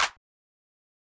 Clap (outside) (1).wav